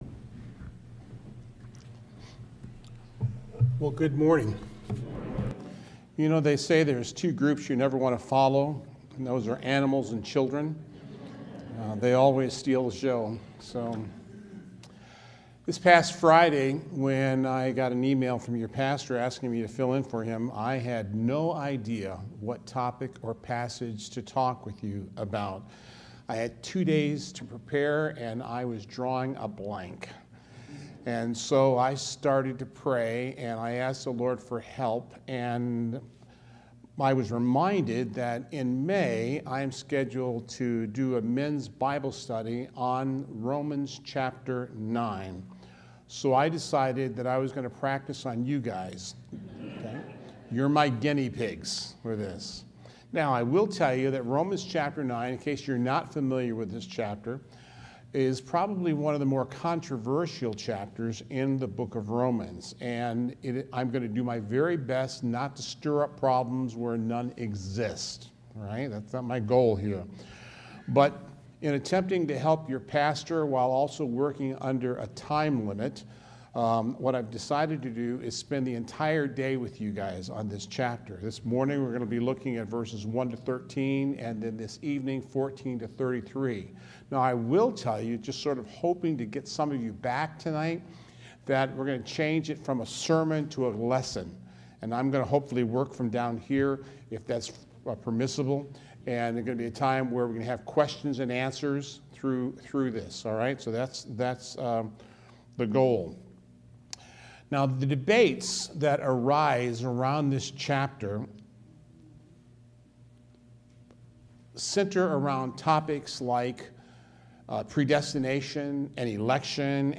Sermons | Decatur Bible Church
Guest Speaker